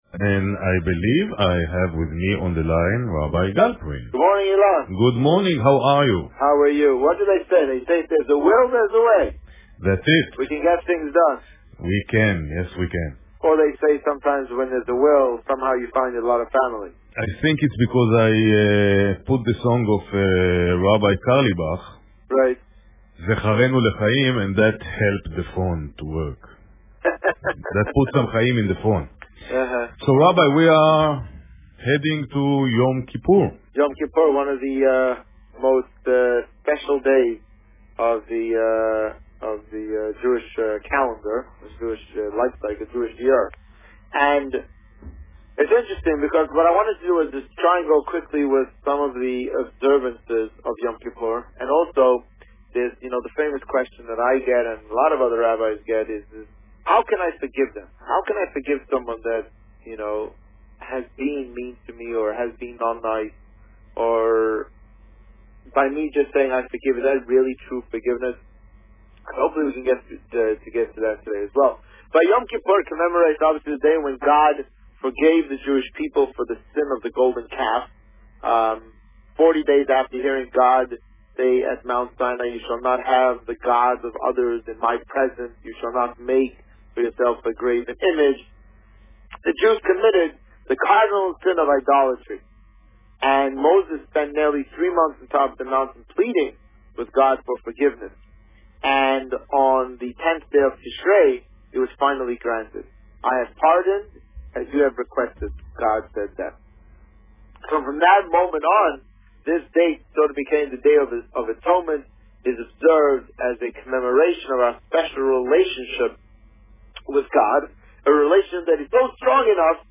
Today, the rabbi spoke about preparations for the Yom Kippur service.  Listen to the interview